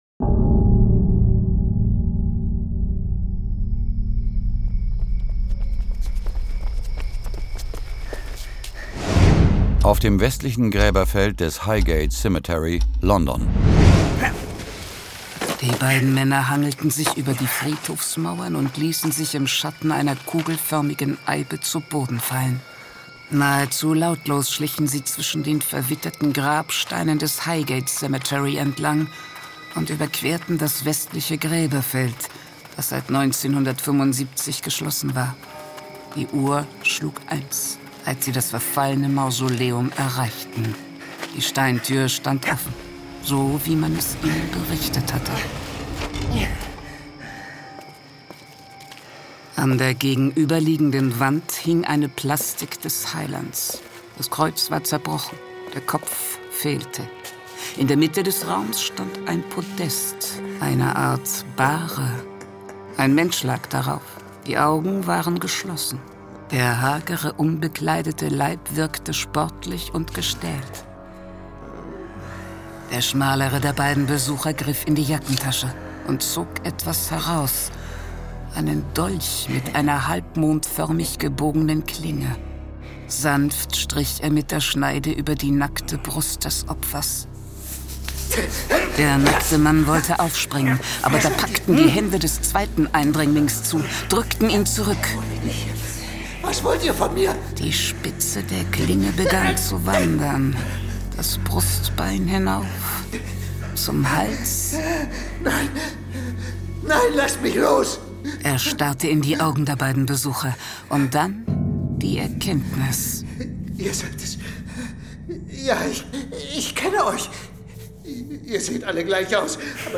John Sinclair Classics - Folge 14 Dämonos. Hörspiel.